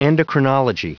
Prononciation du mot endocrinology en anglais (fichier audio)